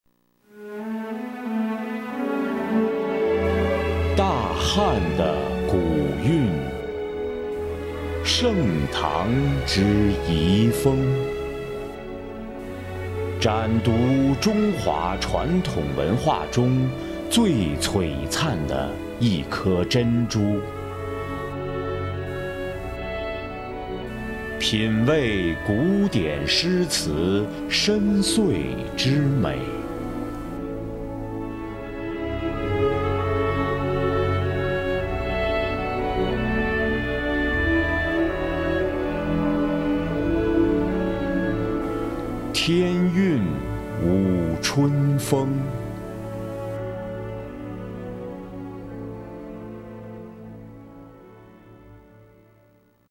Best Voice in Chinese (Mandarin) Warm, Bright, Deep, Smooth and Professional.
Sprechprobe: Industrie (Muttersprache):